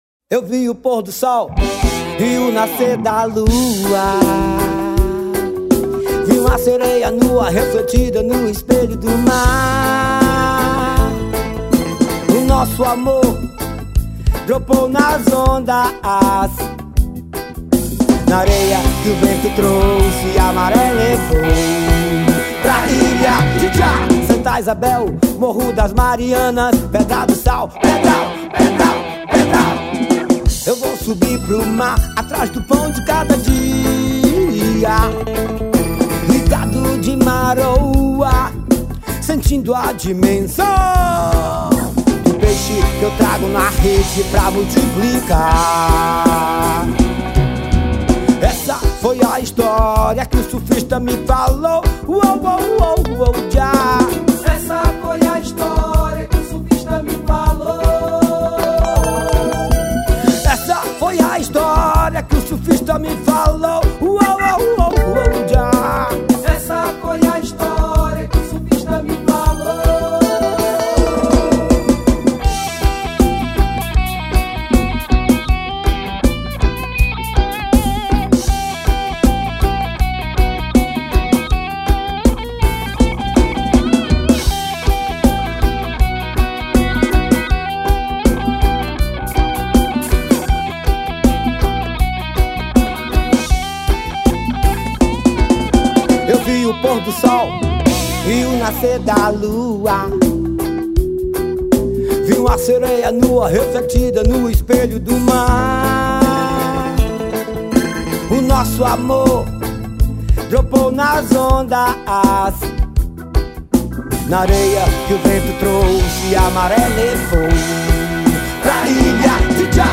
2236   03:11:00   Faixa:     Forró